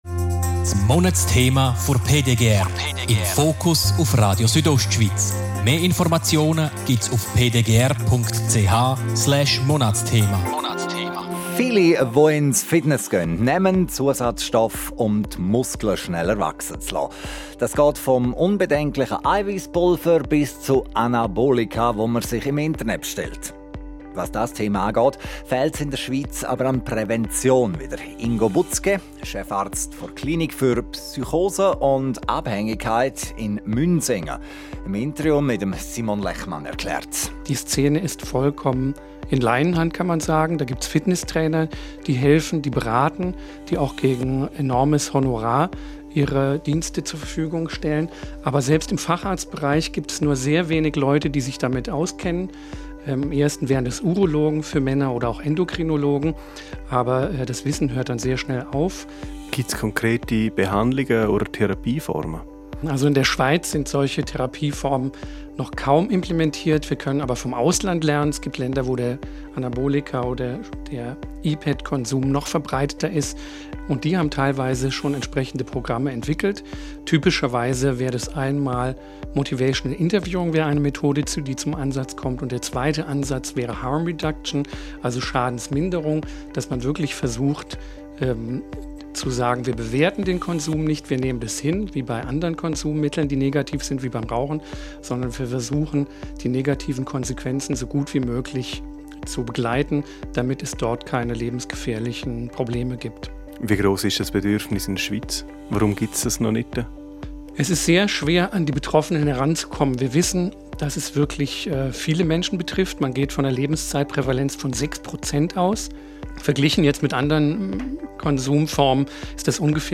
Interview Radio Südostschweiz